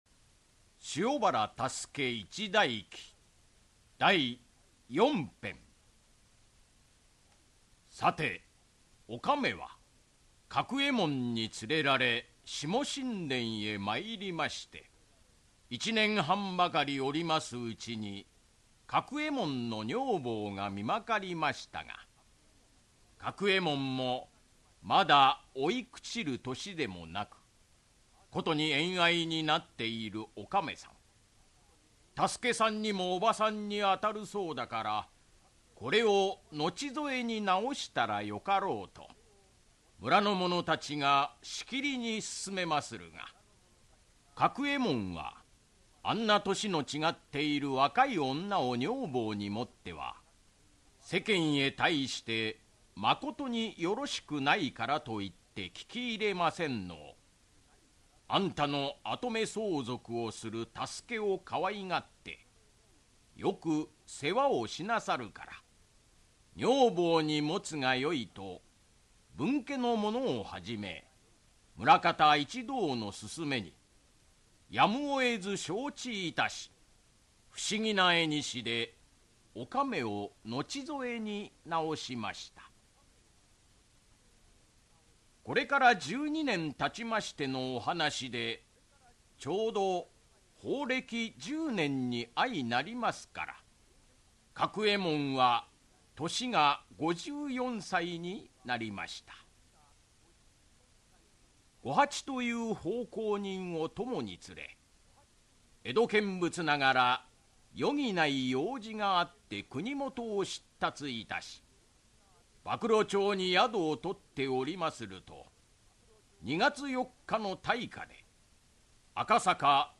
[オーディオブック] 塩原多助一代記-第四・五編-